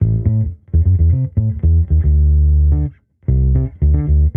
Index of /musicradar/sampled-funk-soul-samples/110bpm/Bass
SSF_PBassProc1_110A.wav